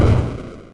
Techmino/media/effect/chiptune/fall.ogg at 89134d4f076855d852182c1bc1f6da5e53f075a4
fall.ogg